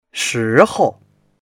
shi2hou4.mp3